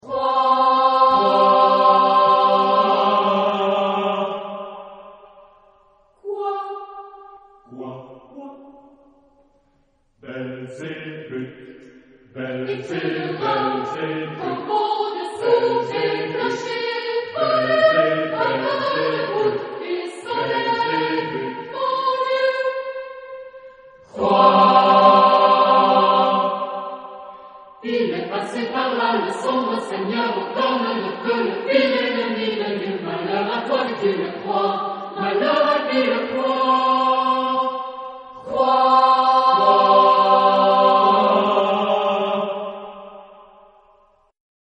Género/Estilo/Forma: Variedades ; Profano
Tipo de formación coral: SATB  (4 voces Coro mixto )
Tonalidad : do menor